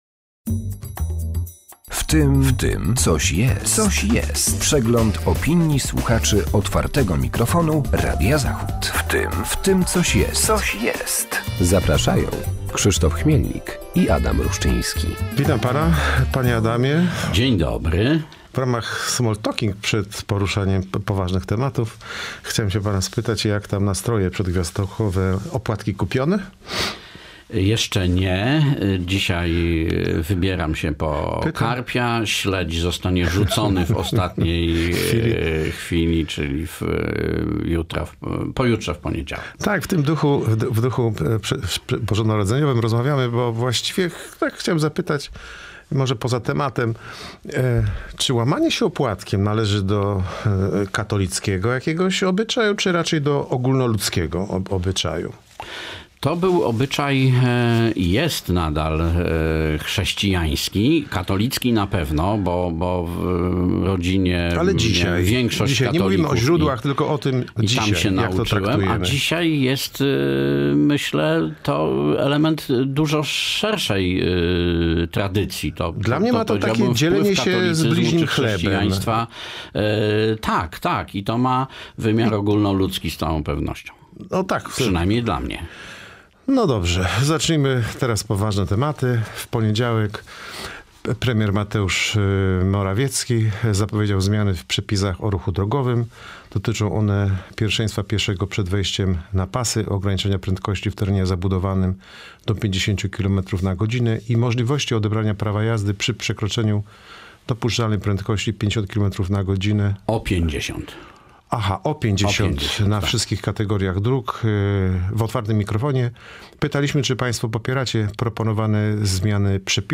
W cotygodniowej audycji przypominamy głosy słuchaczy Otwartego Mikrofonu oraz komentujemy tematy z mijającego tygodnia.